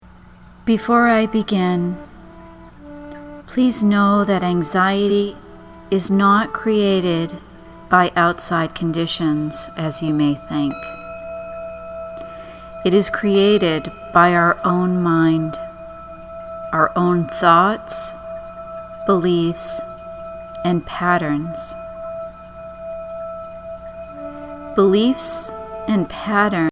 Please note there will be a period of silence during the actual transmission - this is NOT to be skipped.